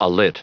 Prononciation du mot alit en anglais (fichier audio)
Prononciation du mot : alit